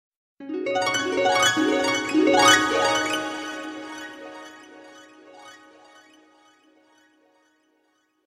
SMS Tone